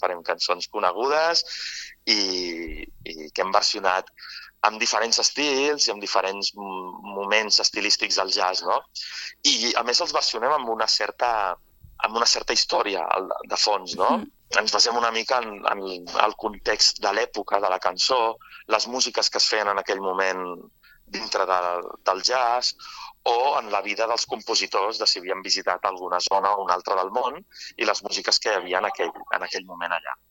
amb qui fem l’entrevista.